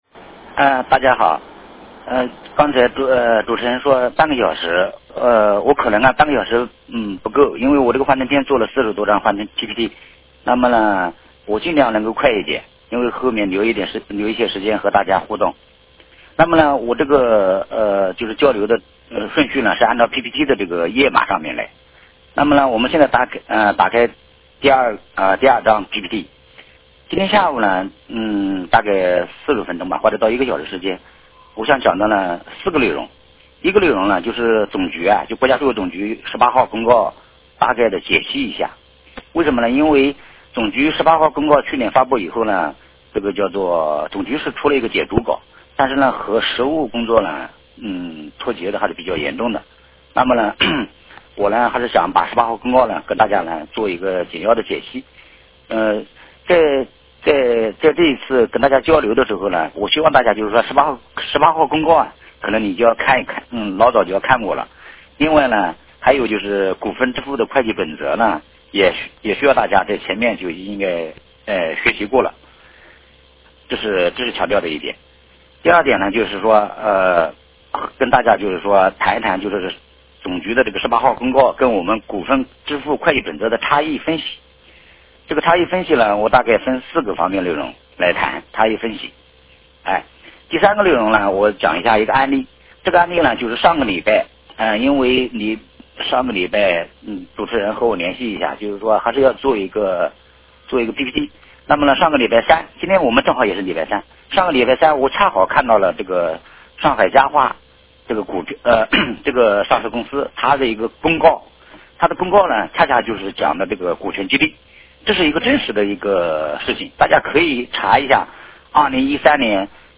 电话会议